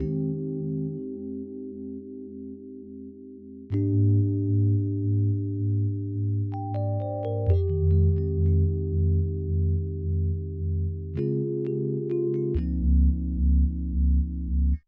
01 rhodes B2.wav